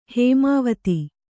♪ hēmāvati